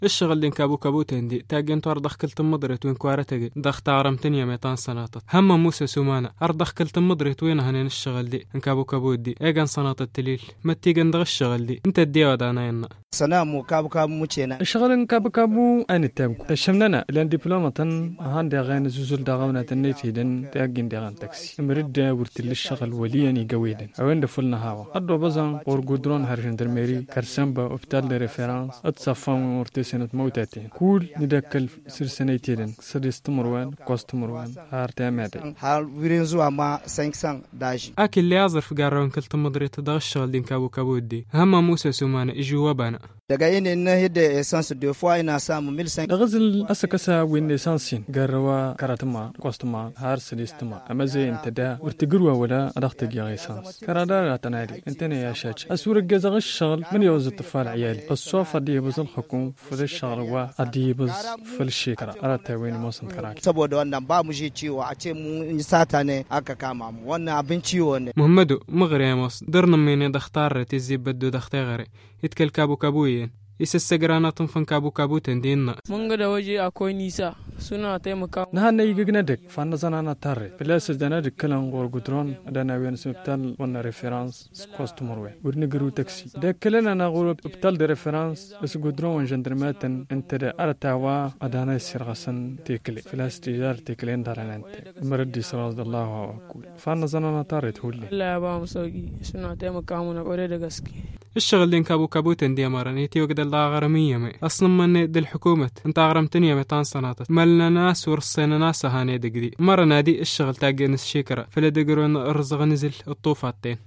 Suivons son reportage.